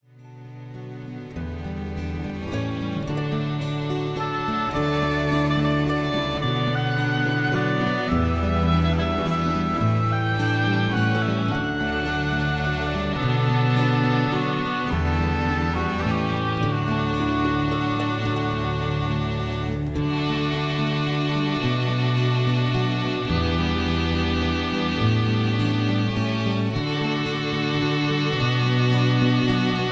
Key-D
Tags: backing tracks , irish songs , karaoke , sound tracks